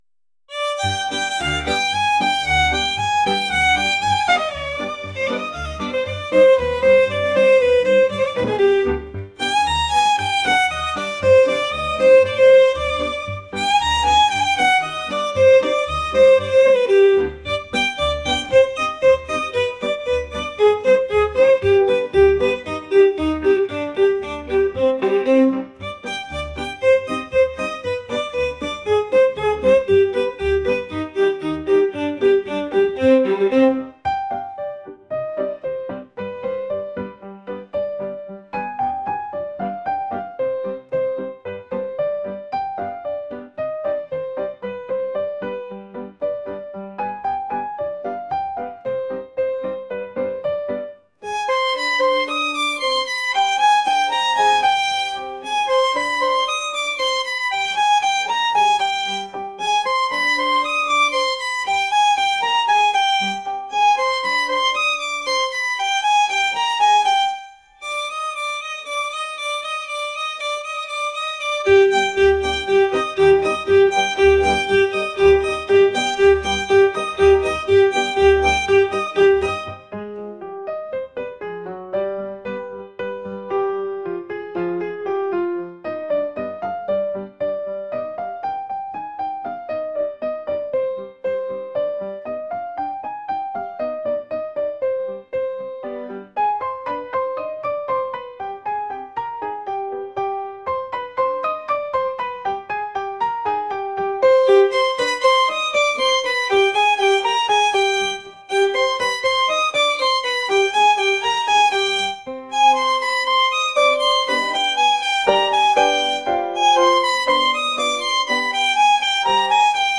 traditional | lively